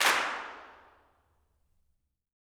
CLAPS 18.wav